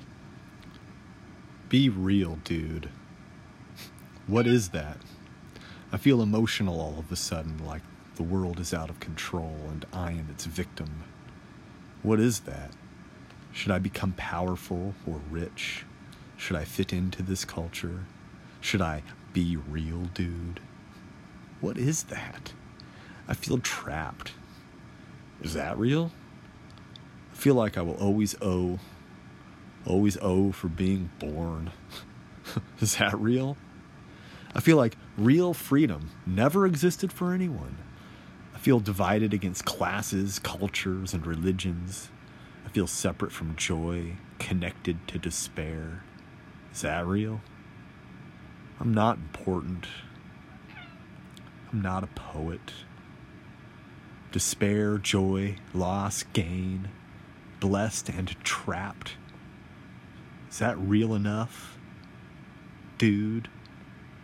Poetry 1 Minute